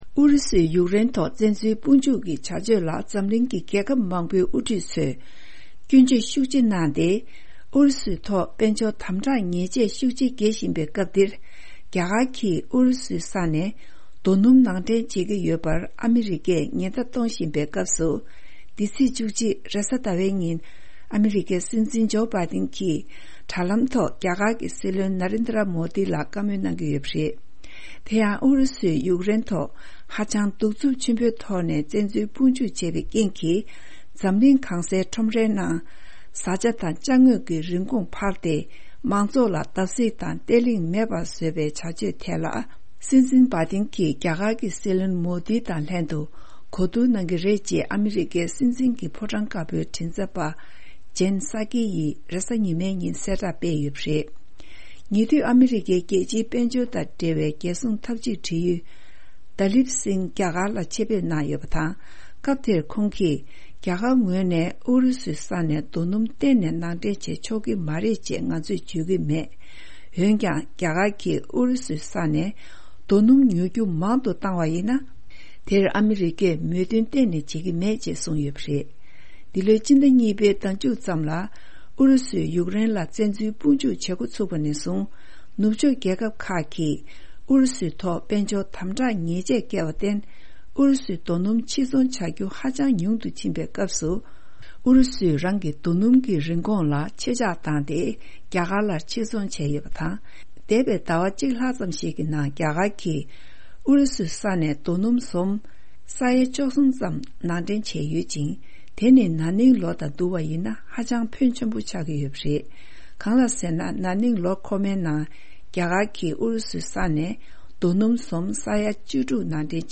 ཕྱོགས་བསྒྲིགས་དང་སྙན་སྒྲོན་ཞུ་ཡི་རེད།།